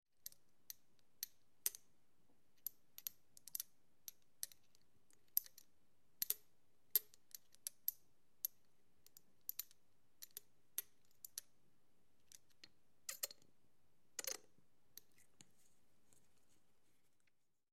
На этой странице собраны звуки капельницы в разных вариантах: от монотонного стекания жидкости до фонового шума больничной палаты.
Держим ампулы для катетера в руке